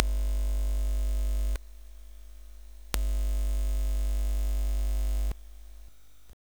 BUZZ      -L.wav